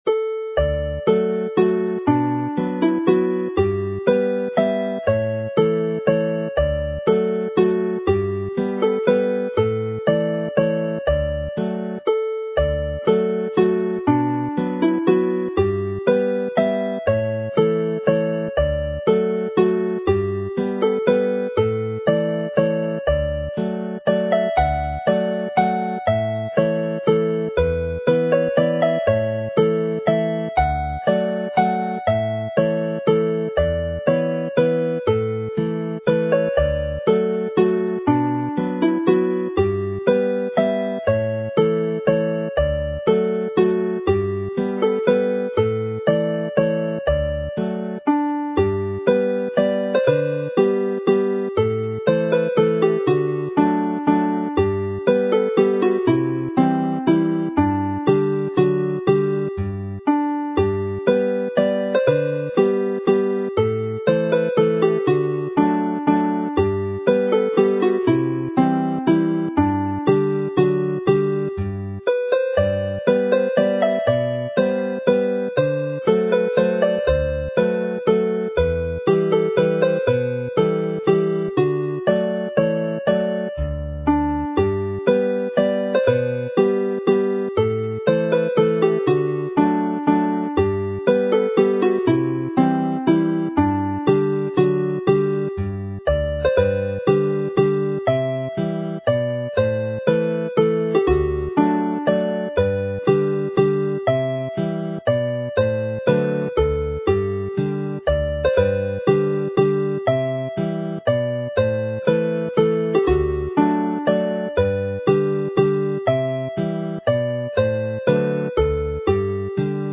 Play the set - once through each tune